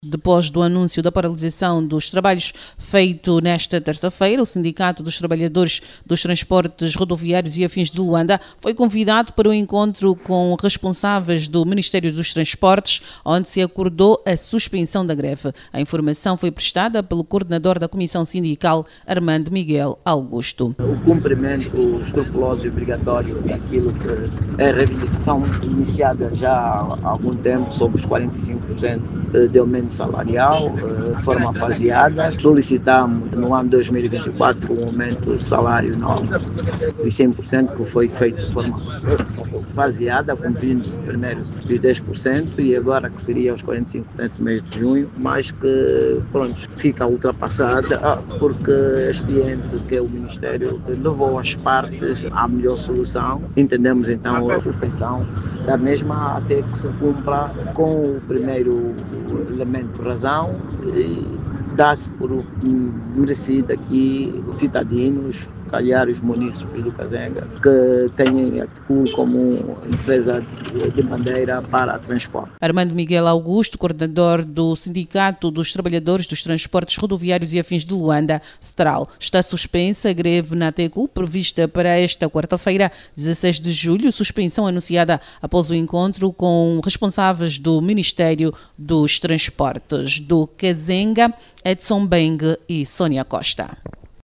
Assim hoje os funcionários da TCUL, não realizaram a greve, que estava prevista e estão a trabalhar a cem por cento. Da Radio Cazenga a reportagem